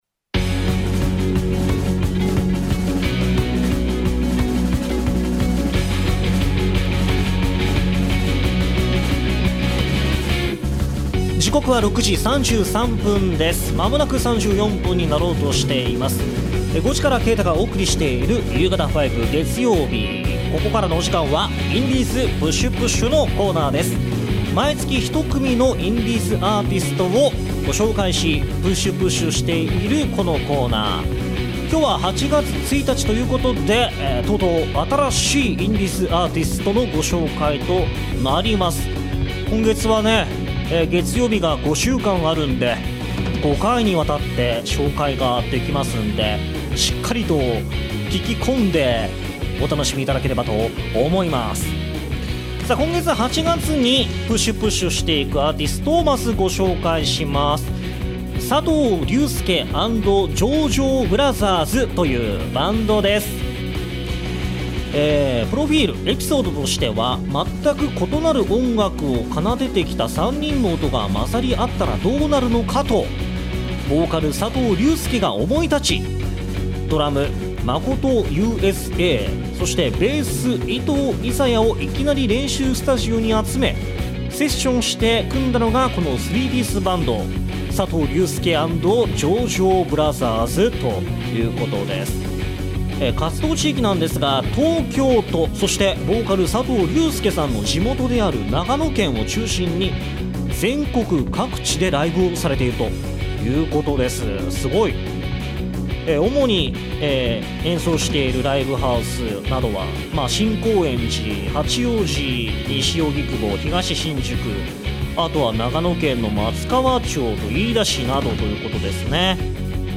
今週の放送同録音源はこちら↓ 8月1日インディーズPUSH×2